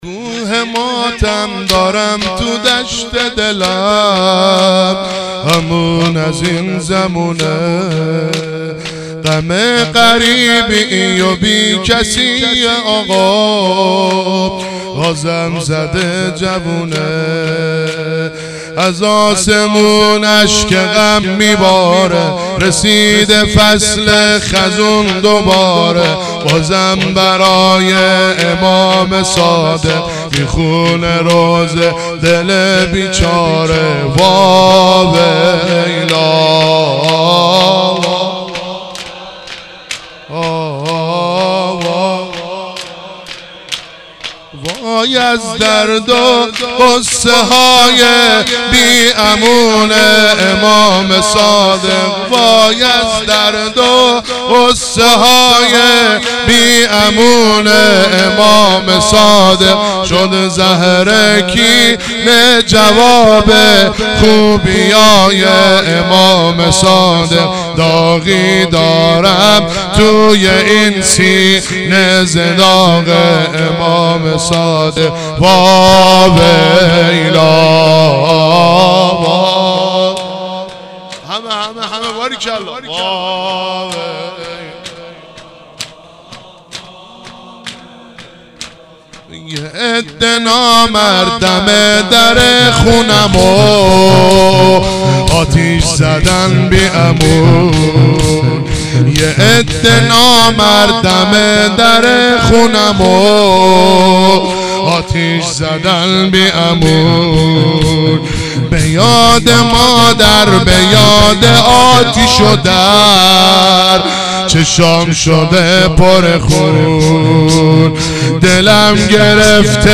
فایل های صوتی مراسم شهادت امام صادق (علیه السلام )94
sh-emam-sadegh-94-zamineh1.mp3